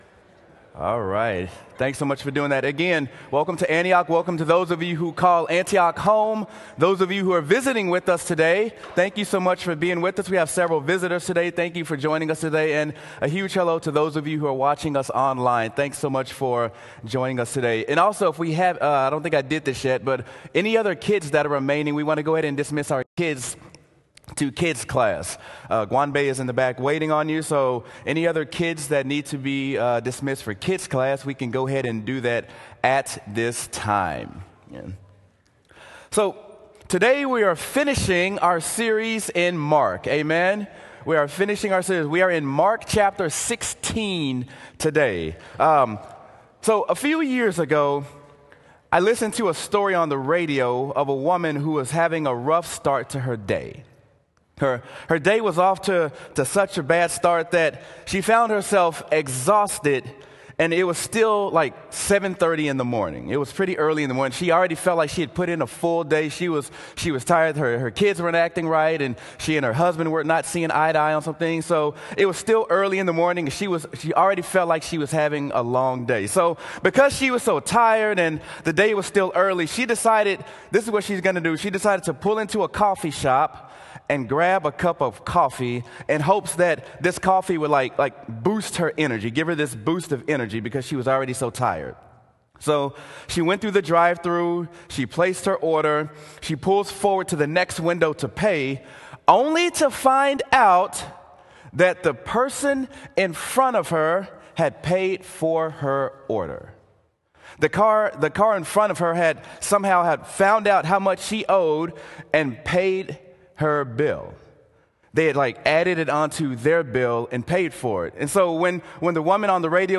Sermon: Jesus Changes Everything!
sermon-jesus-changes-everything.m4a